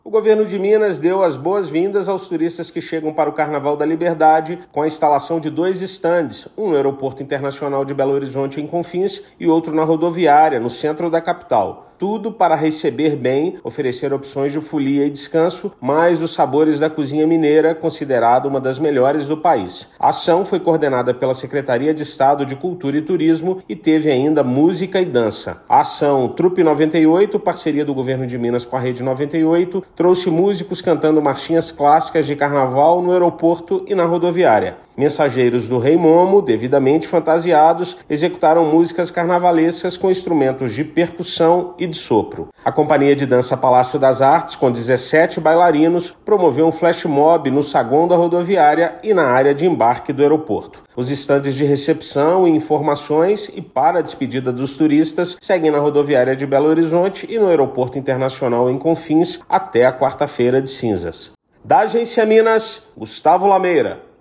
Turistas encontram estandes informativos sobre o estado no aeroporto e na rodoviária. Ouça matéria de rádio.